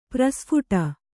♪ prasphuṭa